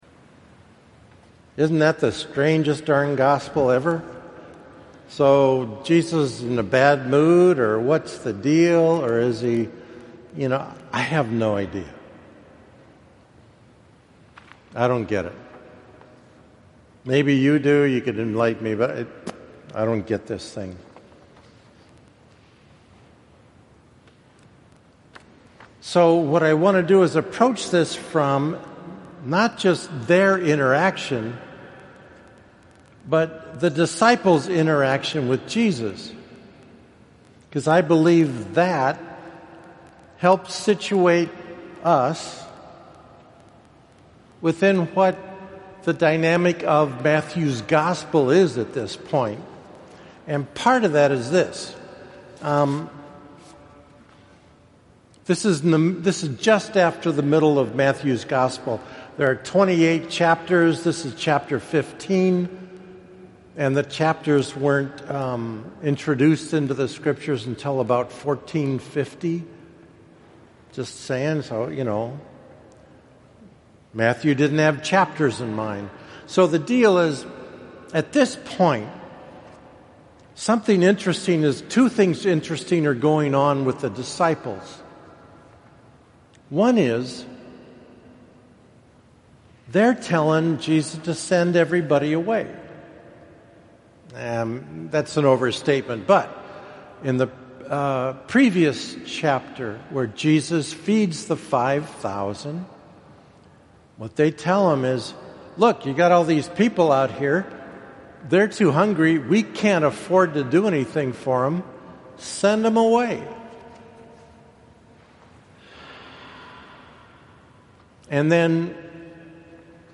This audio version of my homily addresses this part.